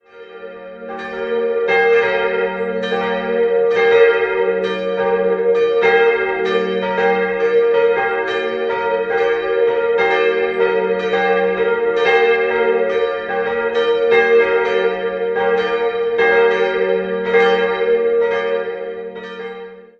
Das Untergeschoss des Kirchturms stammt noch aus gotischer Zeit, das Langhaus und das achteckige Turmobergeschoss wurden in den Jahren 1740/41 neu errichtet. 3-stimmiges Geläute: g'-b'-d'' Die beiden größeren Glocken wurden 1950 von Karl Czudnochowsky in Erding gegossen, die kleine stammt aus dem Jahr 1724.